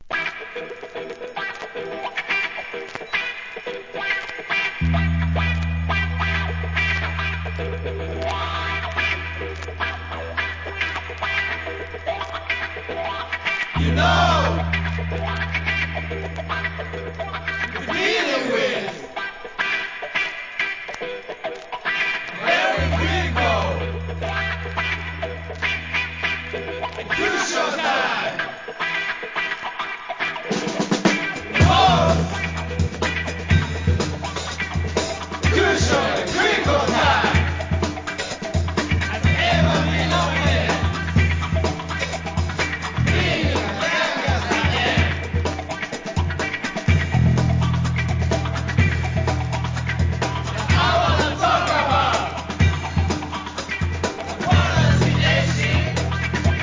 ¥ 660 税込 関連カテゴリ SOUL/FUNK/etc...